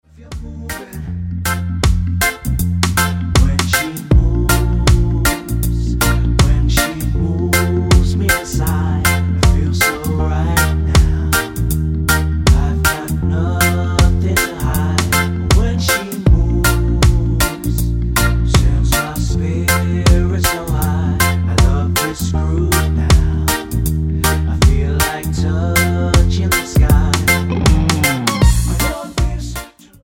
--> MP3 Demo abspielen...
Tonart:Bb mit Chor